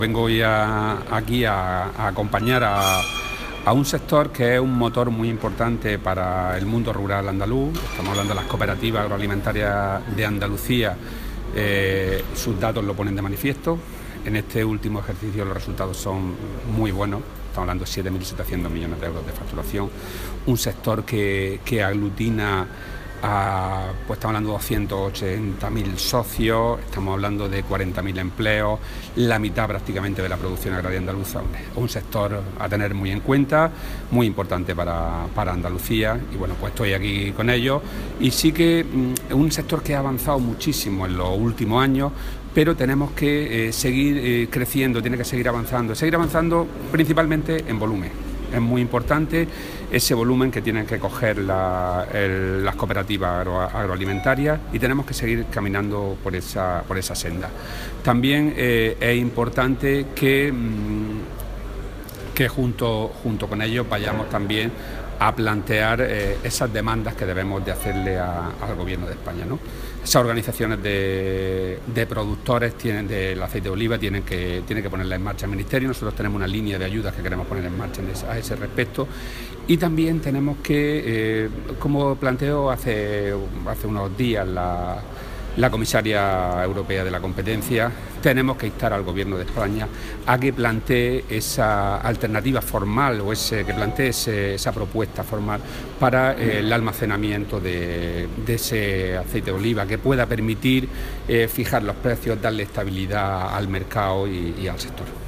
Rodrigo Sánchez Haro durante la clausura de la Asamblea General de Cooperativas Agro-alimentarias de Andalucía
Declaraciones de Rodrigo Sánchez Haro sobre el sector cooperativo agroalimentario de Andalucía